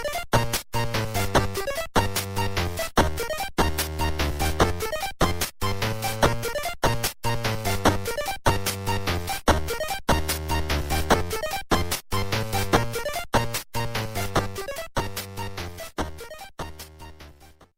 Self-recorded